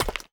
Free Fantasy SFX Pack
Chopping and Mining
mine 3.ogg